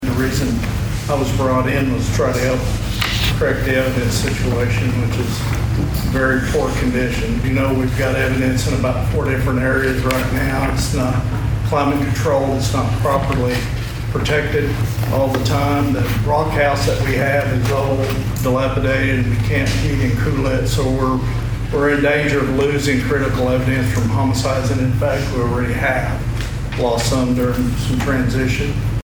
At Monday's Osage County Commissioners meeting, it was brought to the Board's attention as to the poor conditions the evidence room is in at the sheriff's office.